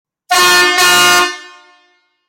Buzinas a Ar para Bicicletas Big Jumbo
• 02 cornetas;
• Intensidade sonora 130db;
• Acionamento através de bomba manual;
Som da Buzina